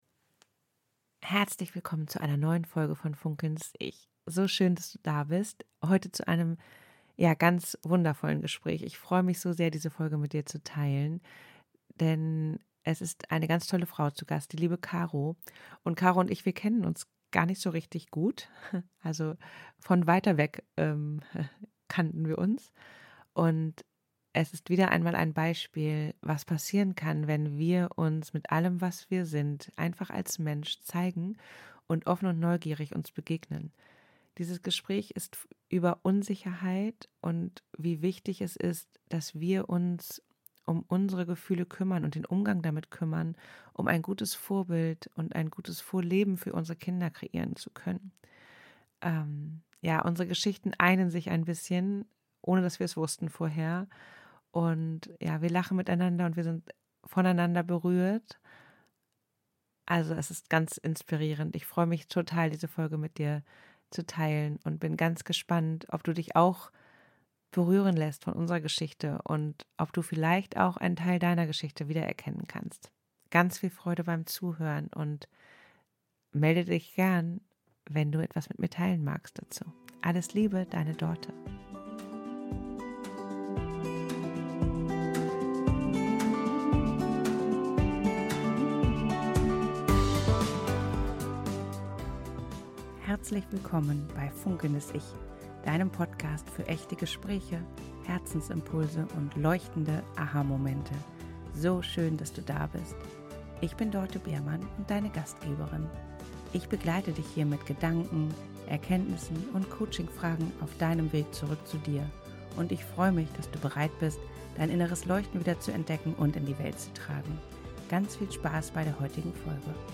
Dieses Gespräch ist ehrlich, tief, berührend, authentisch - einfach zwei tolle Frauen, die "einfach sie selbst sind" - und sich damit zeigen.